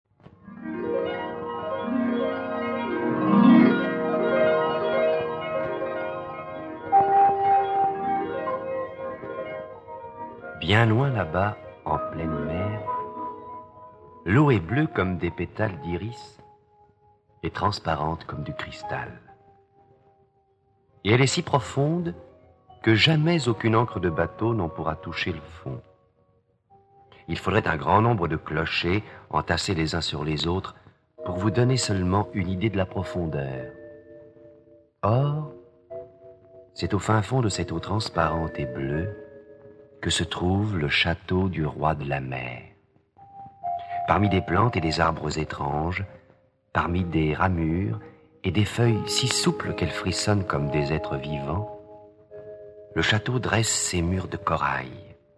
Diffusion distribution ebook et livre audio - Catalogue livres numériques
Enregistrement original